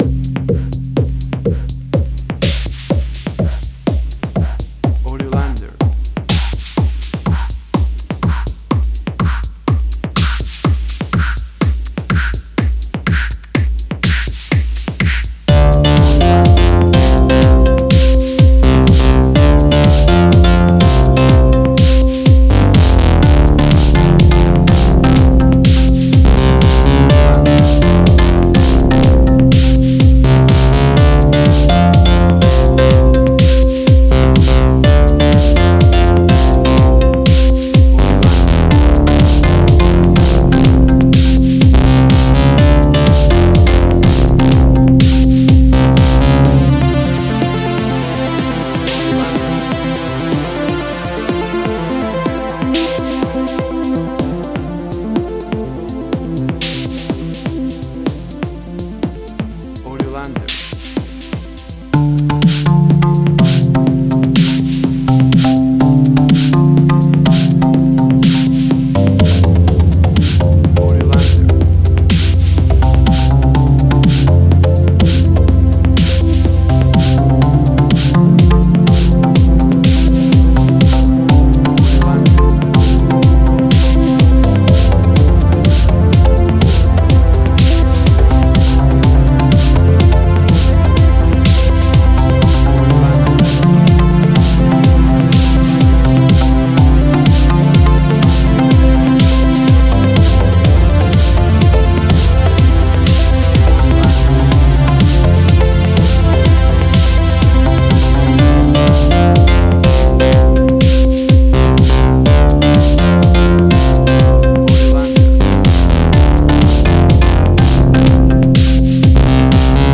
WAV Sample Rate: 16-Bit stereo, 44.1 kHz
Tempo (BPM): 125